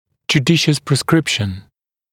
[ʤuː’dɪʃəs prɪs’krɪpʃn][джу:’дишэс прис’крипшн]правильное назначение, обоснованное назначение (напр. препарата, лечения)